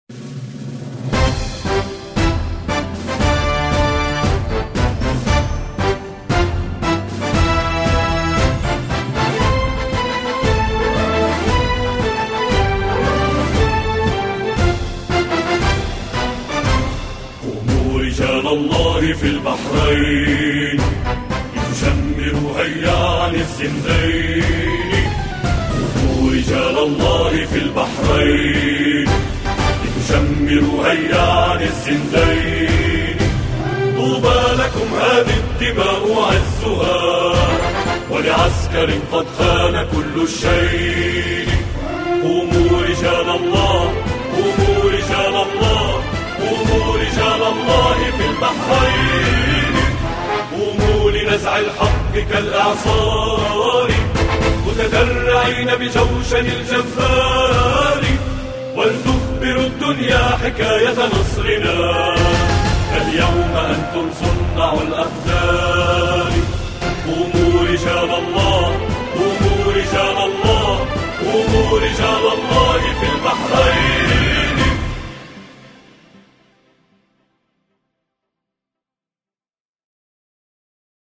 انشودة وطنية
أناشيد بحرينية